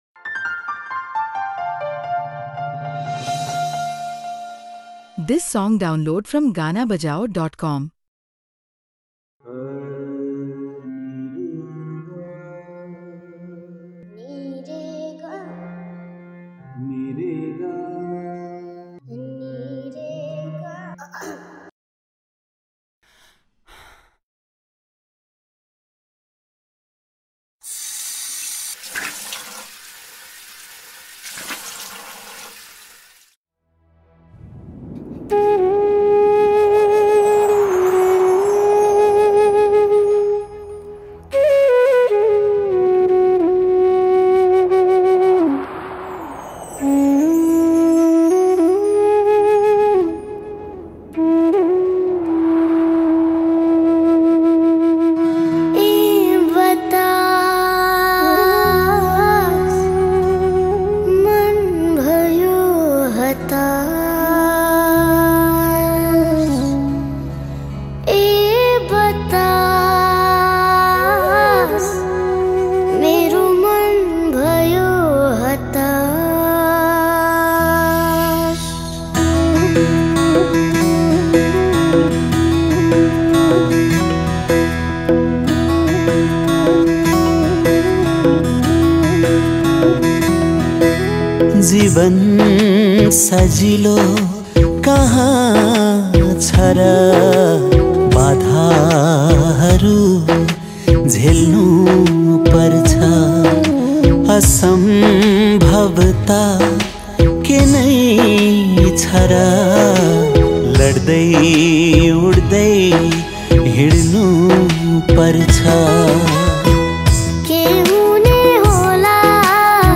# Nepali Adhunik Songs